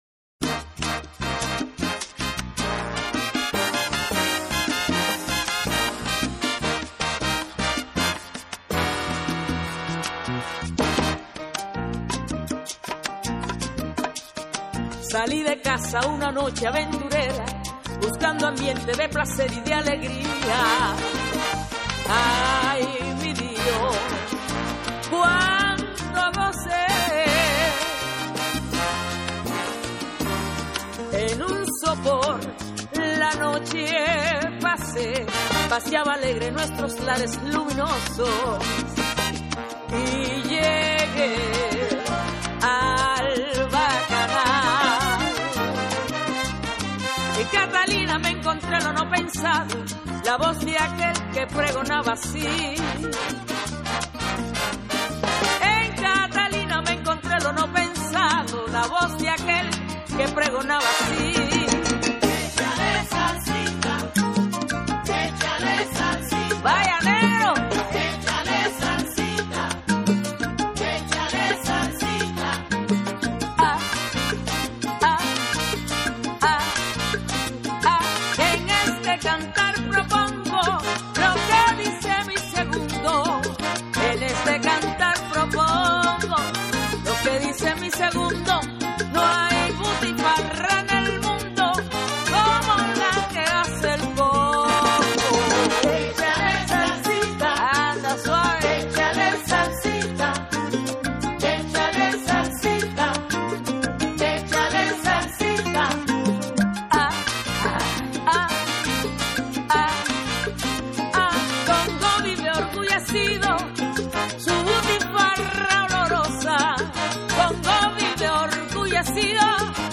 While the rhythms and percussion are identical to the more contemporary salsa sound, absent is the full orchestra sound that salsa incorporates as exchanges between American jazz musicians and Cuban musicians became more pronounced during the mambo craze of the 1950's. A more contemporary rendition of "Echale Salsita" can be heard